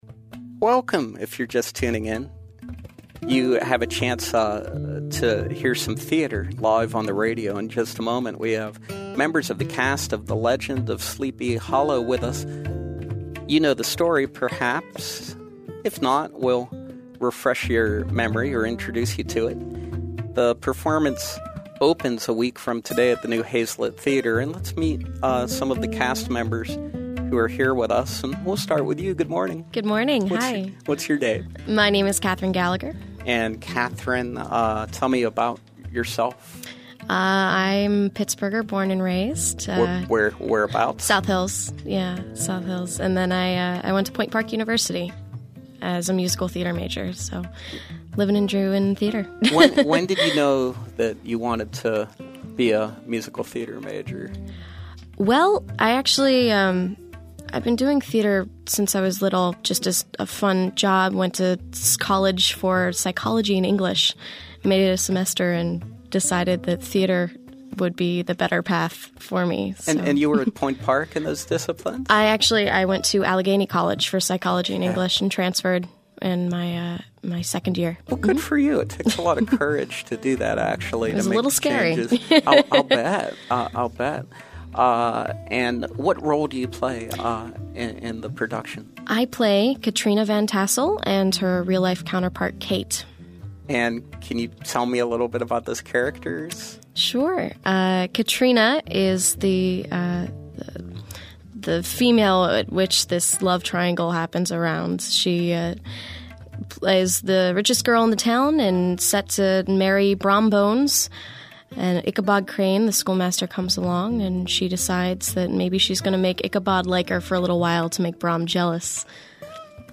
We’re pleased to welcome members of the cast of The Legend of Sleepy Hollow as they perform a scene from this classic story.